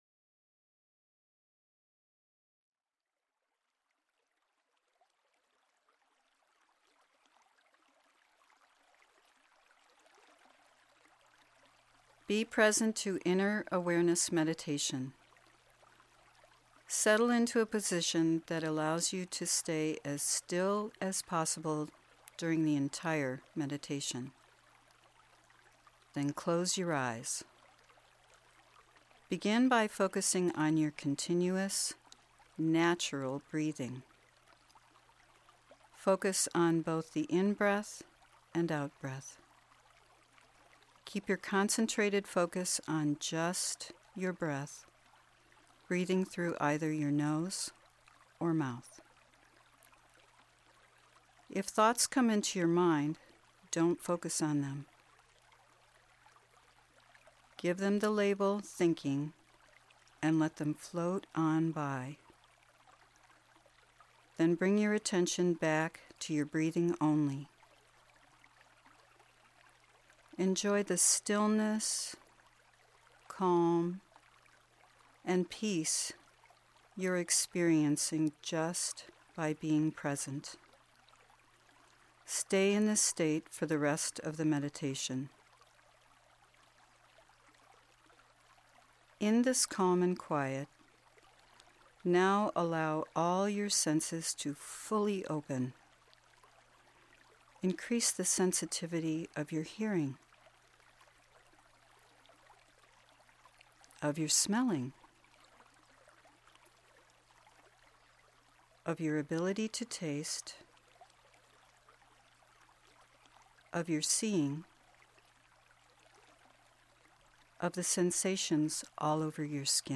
Download Meditation MP3